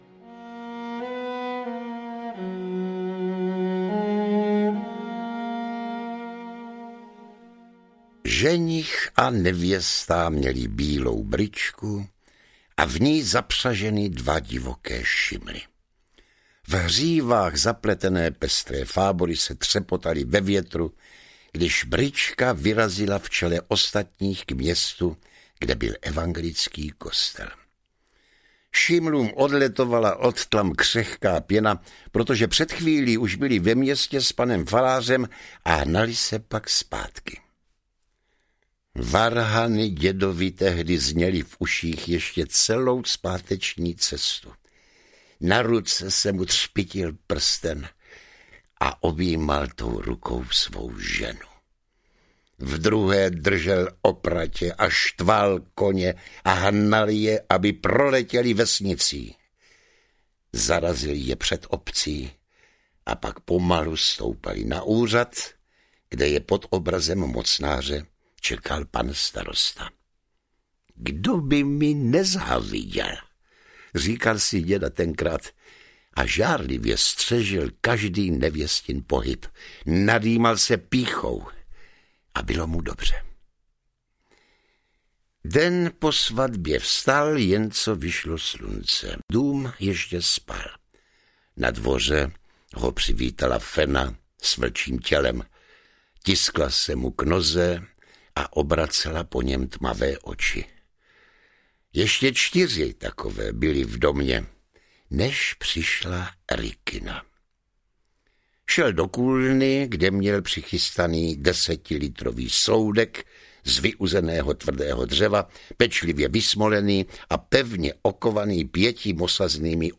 Stín audiokniha
Ukázka z knihy